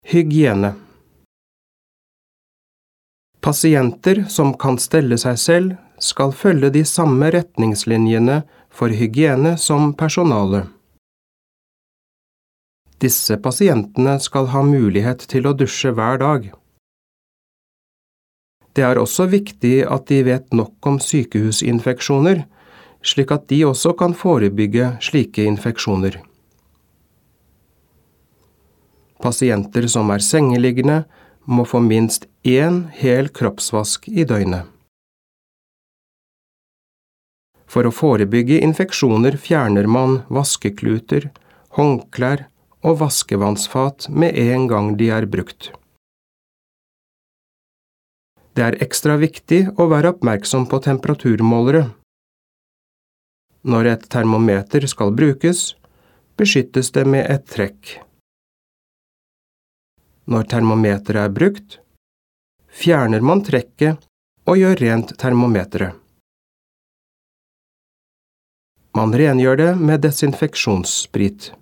Diktat leksjon 5
• Tredje gang leses teksten sammenhengende, og du skal kontrollere det du har skrevet.